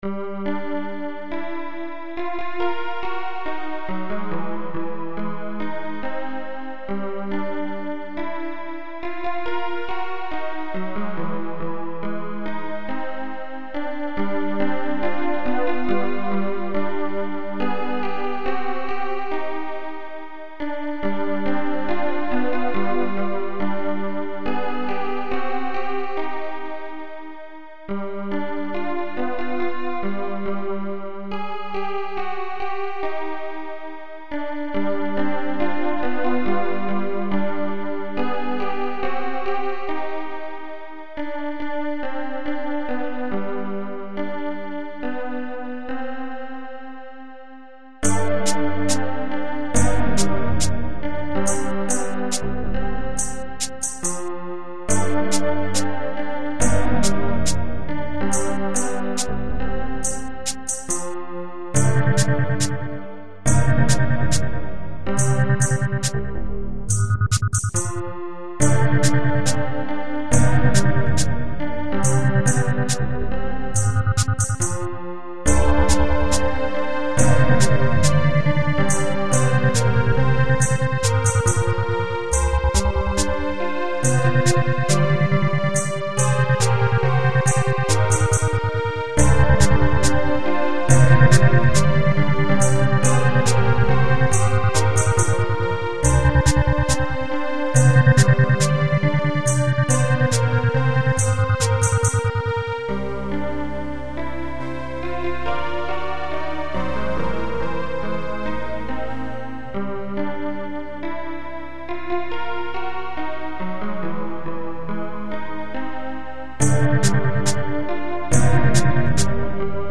Techno
Leftfield/noise
Ambient